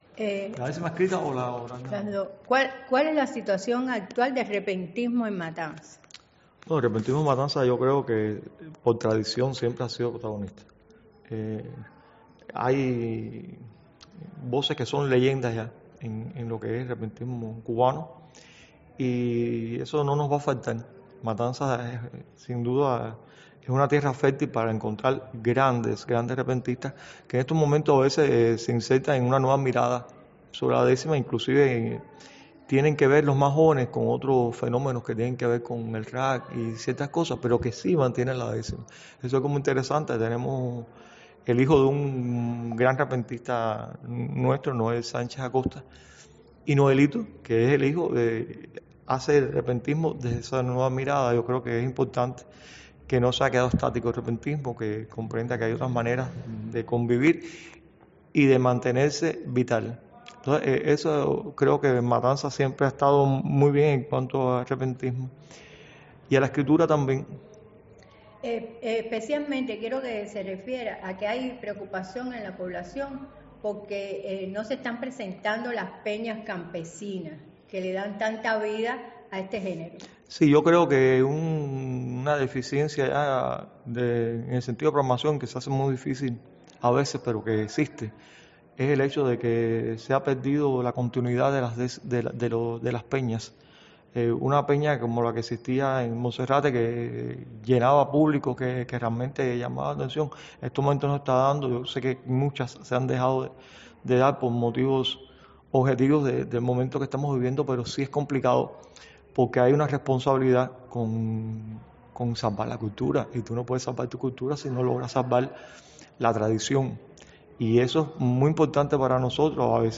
De sus avances y retrocesos conversamos